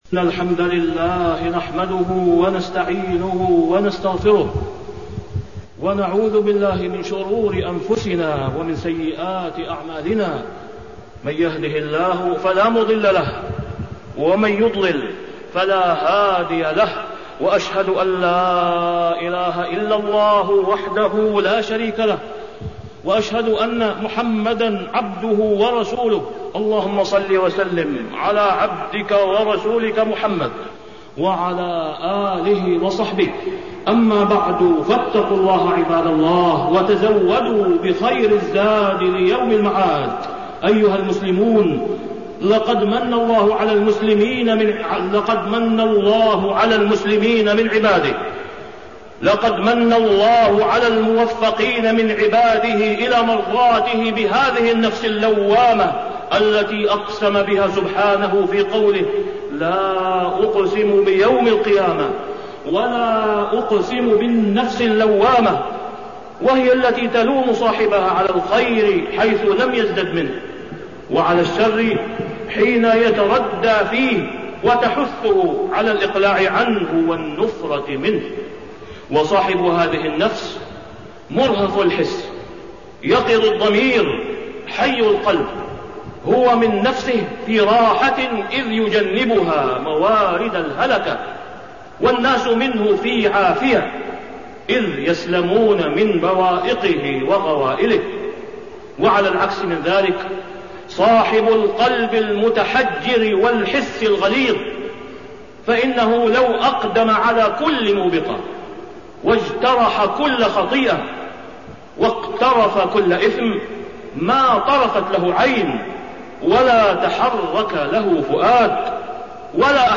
تاريخ النشر ٢٦ ربيع الأول ١٤٣١ هـ المكان: المسجد الحرام الشيخ: فضيلة الشيخ د. أسامة بن عبدالله خياط فضيلة الشيخ د. أسامة بن عبدالله خياط تحذير أرباب الأموال من طغيان الثروات